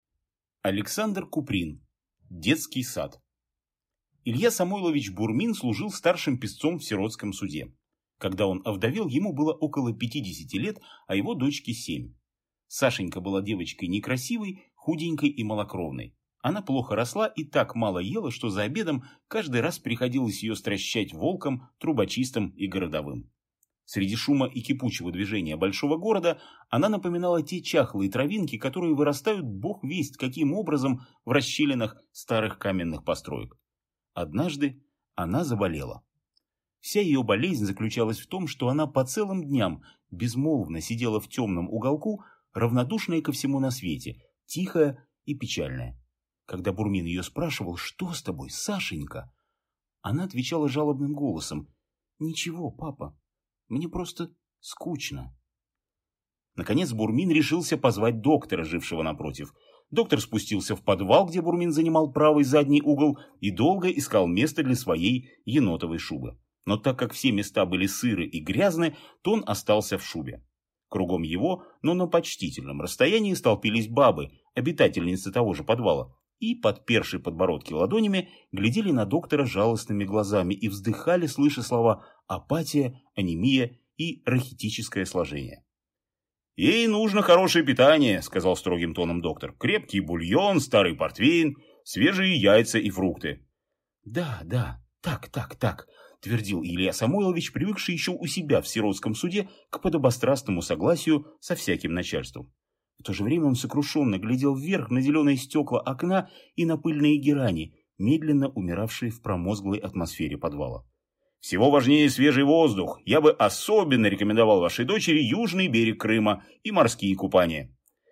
Аудиокнига Детский сад | Библиотека аудиокниг